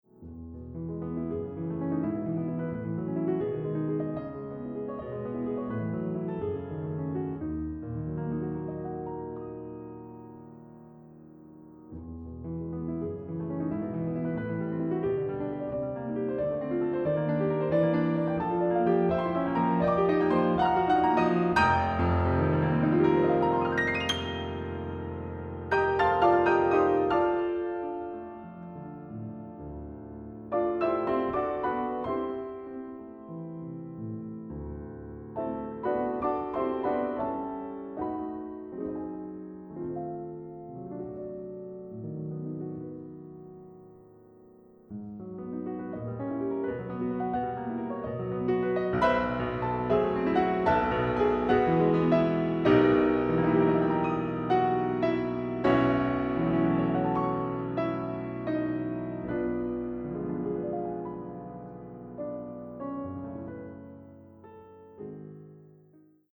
Classical, Keyboard